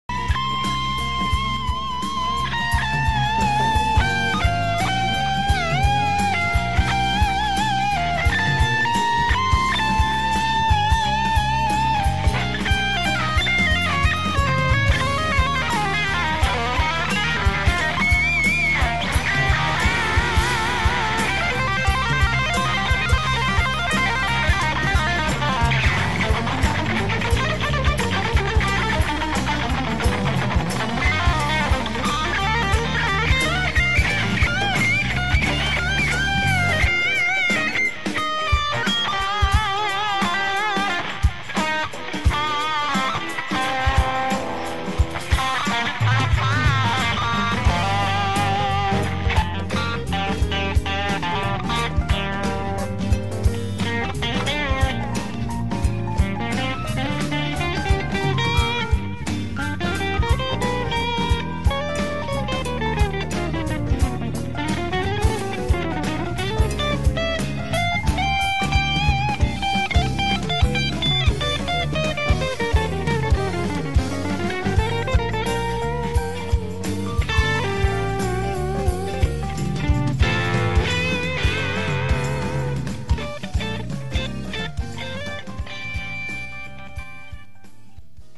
ＢＯＳＳ「ＳＴー２　パワースタック」を使って音を作ってみました。
オーバードライブをかけた音を載せてみます。
ＳＴー２はトレブル・１２時、ベース４時、ゲイン１１時
これ位のクランチにして
リードトーンは主にＳＤー１（スーパーオーバードライブ）を
ゲイン１２時でかけています。
オケはネットラジオに合わせて適当にアドリブ弾いてます。
ギターはＰＲＳのカスタム２２です。